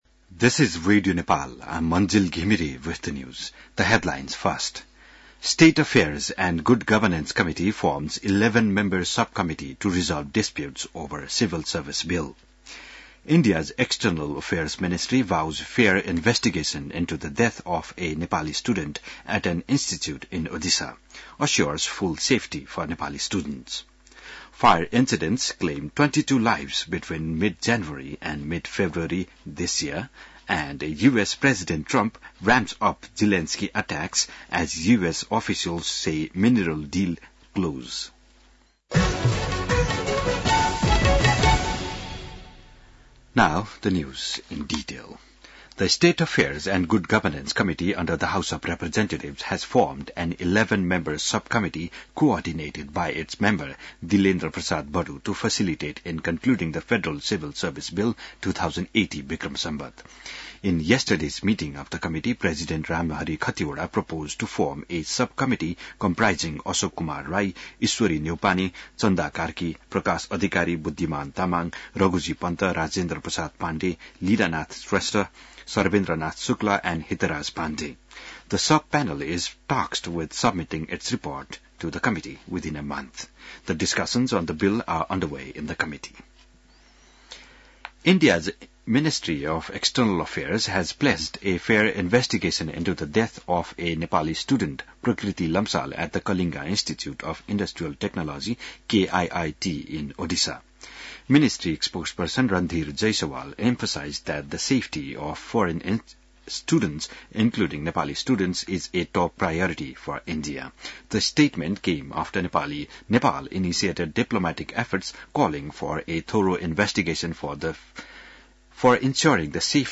बिहान ८ बजेको अङ्ग्रेजी समाचार : ११ फागुन , २०८१